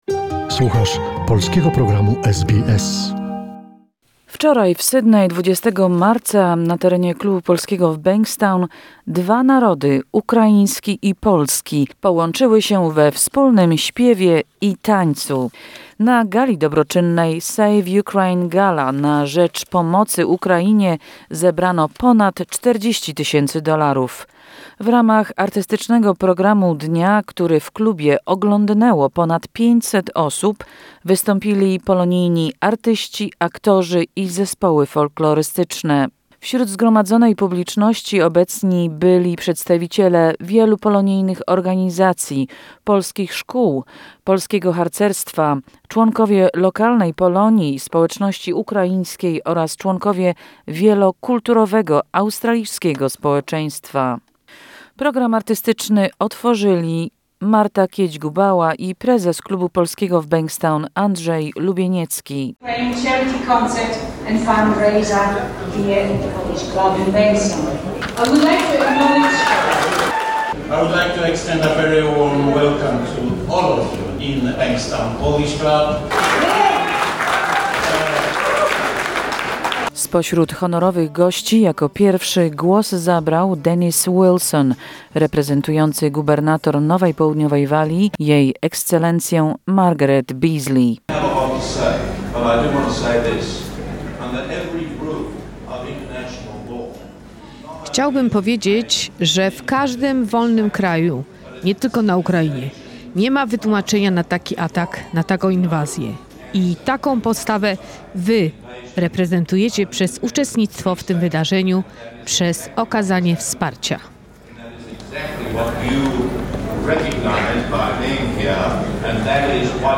Reportaż z Gali Charytatywnej Solidarności z Ukrainą, która odbyła się w niedzielę 20 marca 2022r. W Klubie Polskim w Bankstown polonijna i ukraińska społeczność połączyła się we wspólnym okrzyku SLAVA UKRAINI! Organizatorzy zapowiedzieli, że zebrane $42,500 zostaną przeznaczone na zakup sprzętu medycznego dla walczącej Ukrainy.